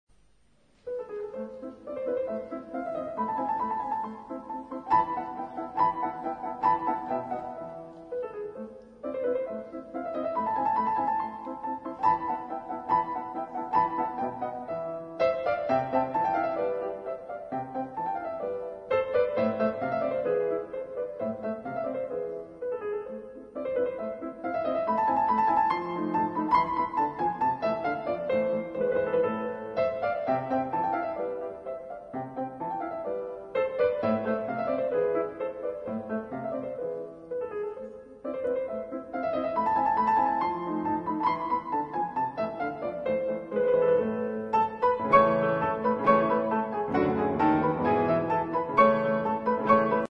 at Wigmore Hall